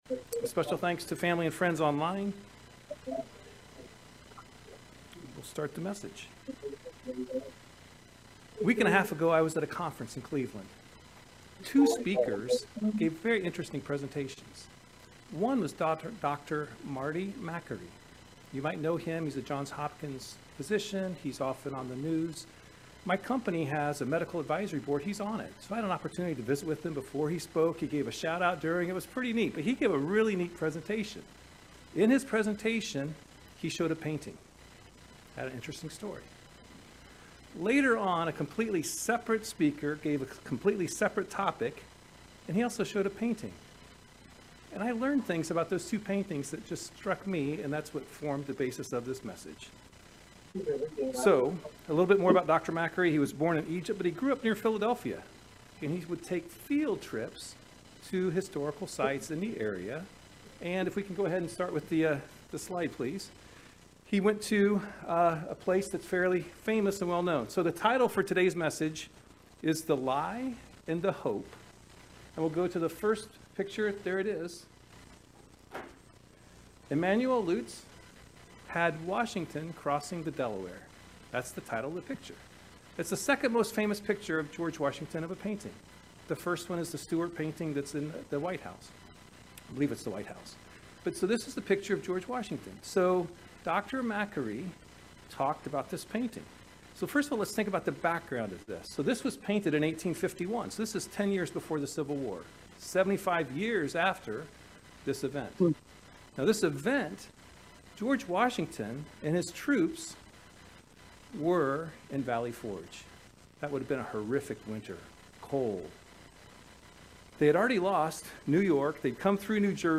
Join us for this very interesting Sermon about the lies Satan spreads , and the hope we have in God.